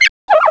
pokeemerald / sound / direct_sound_samples / cries / patrat.aif